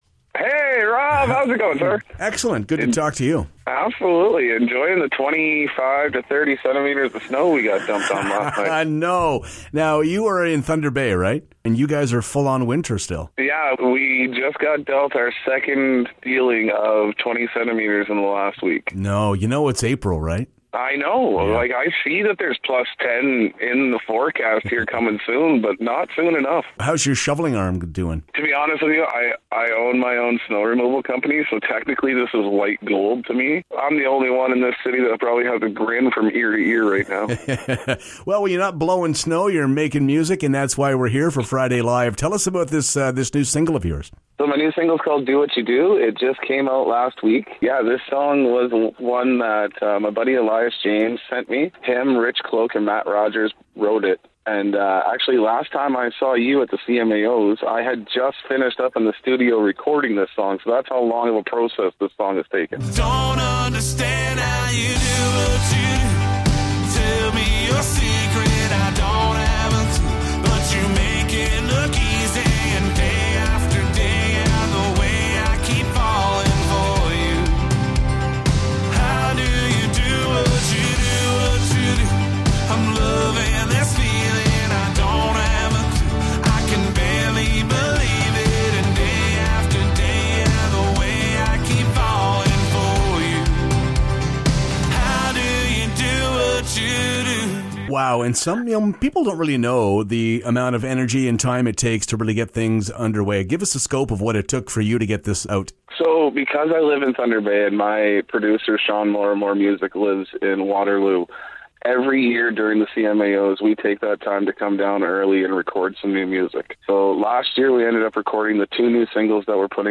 Tune in every Friday morning for weekly interviews, performances, everything LIVE!